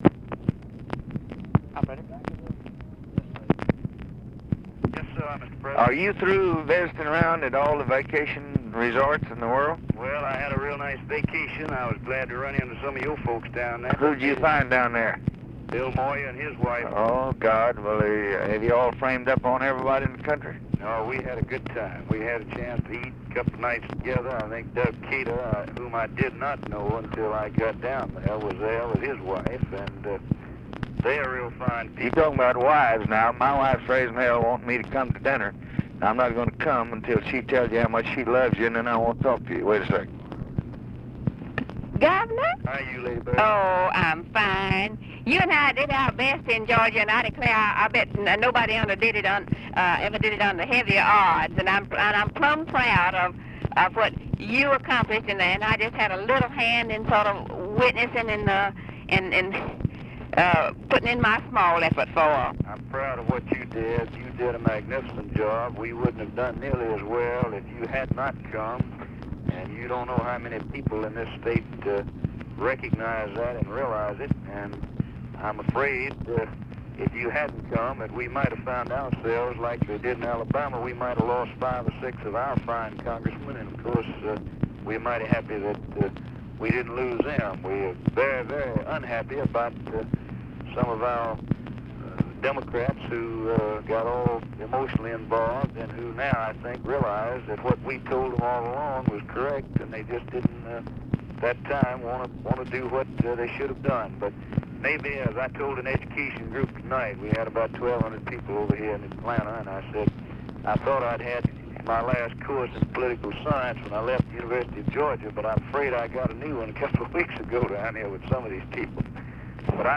Conversation with CARL SANDERS and LADY BIRD JOHNSON, November 12, 1964
Secret White House Tapes